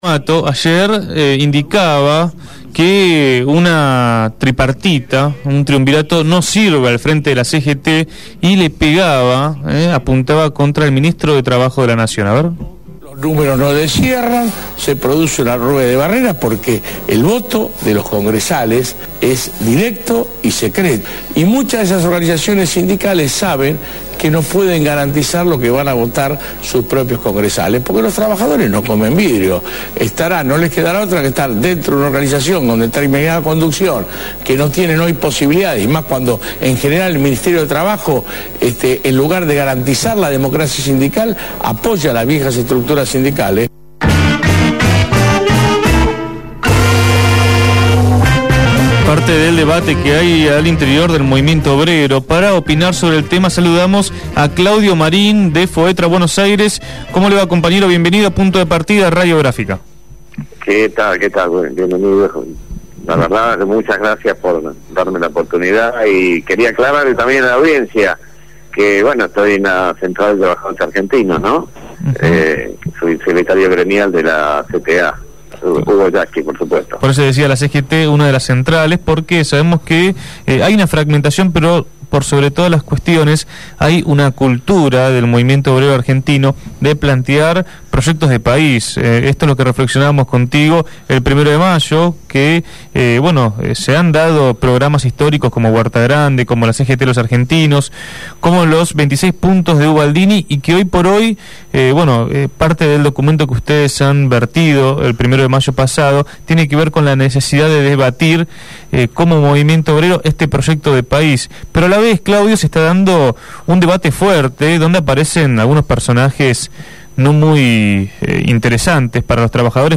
habló en Punto de Partida.